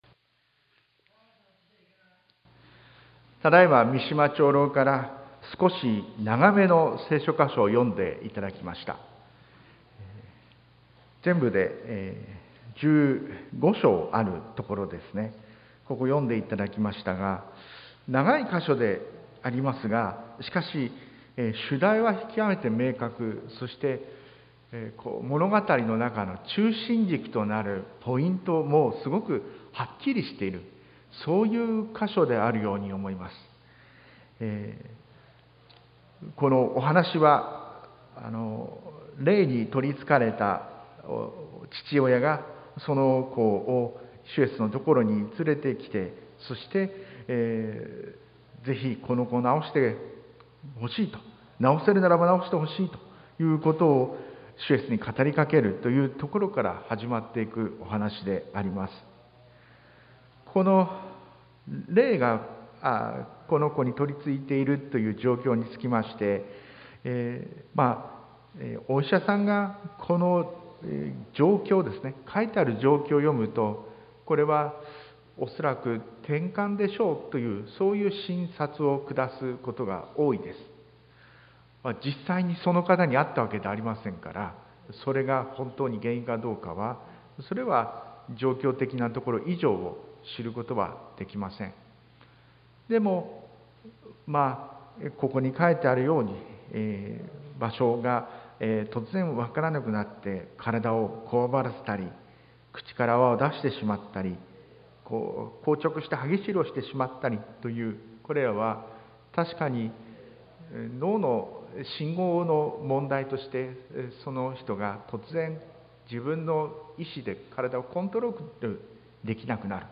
sermon-2021-10-10